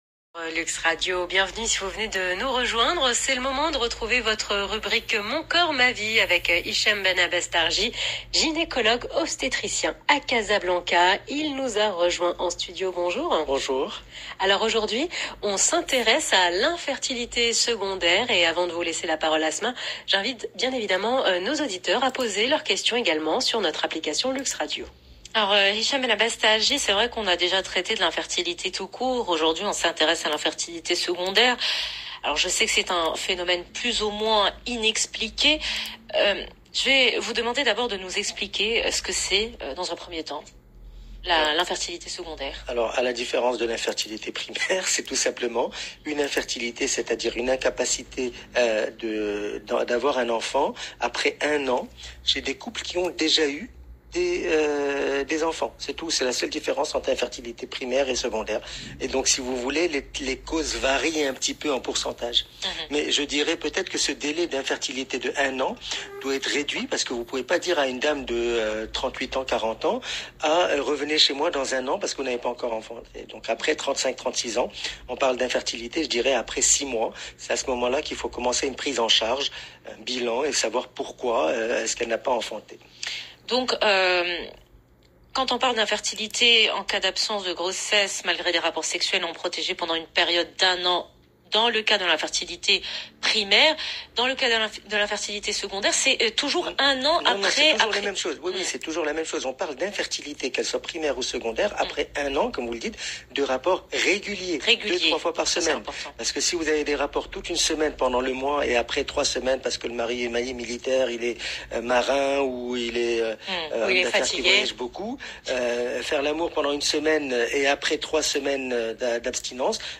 J’en parle dans cette interview de l’heure essentielle sur LUXE RADIO du 09 mars 2021